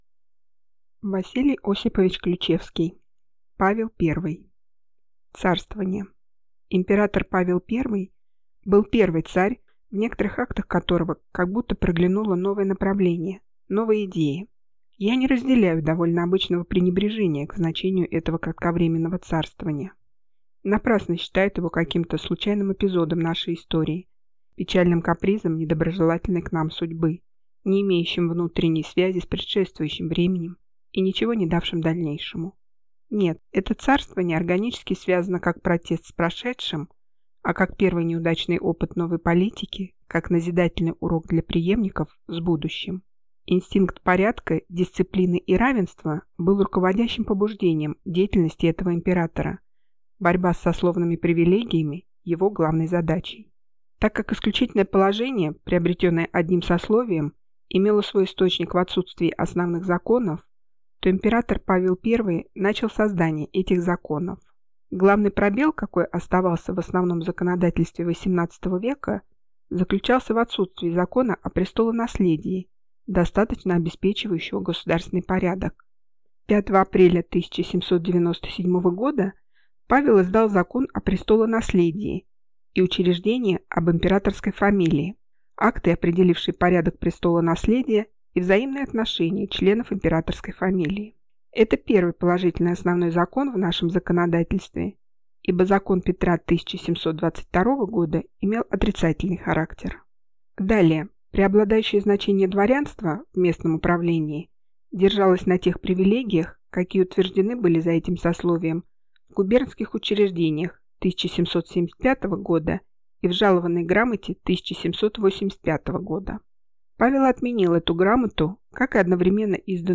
Аудиокнига Павел I | Библиотека аудиокниг